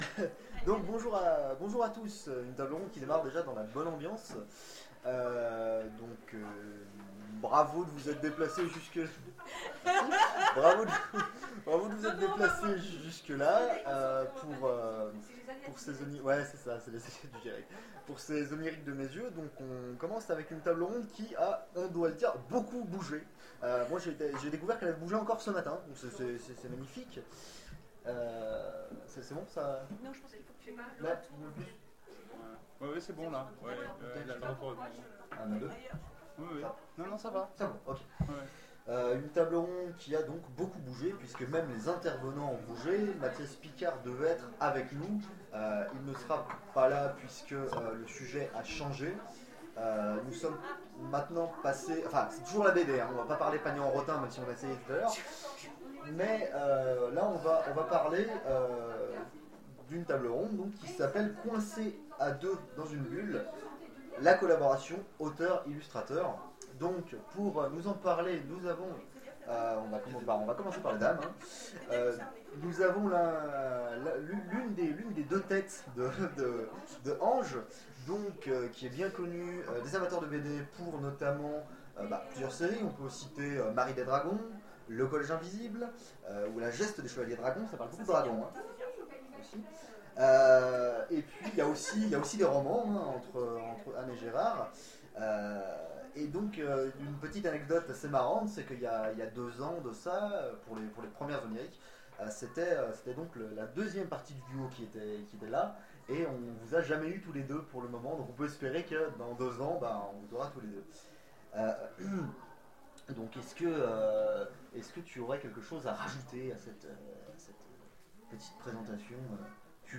Les Oniriques 2015 : Table ronde Coincés à deux dans une bulle...
Mots-clés bande dessinée Conférence Partager cet article